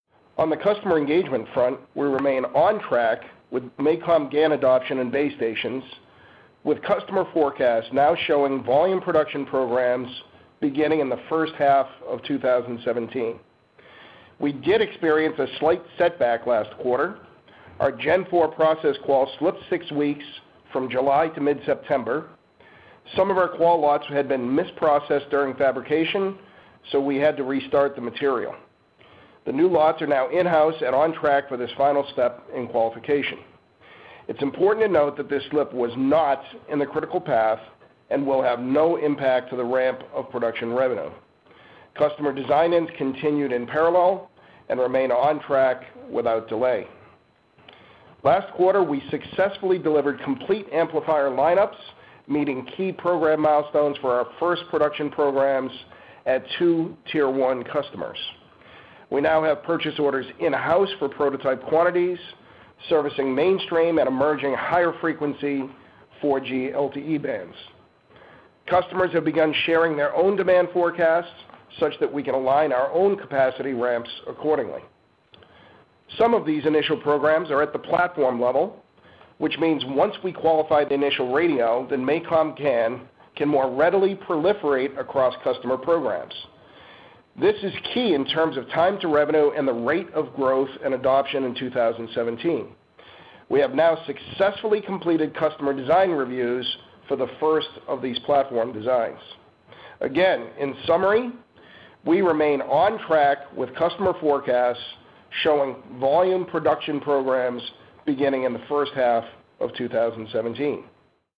During MACOM's fiscal Q3 earnings call on July 26, 2016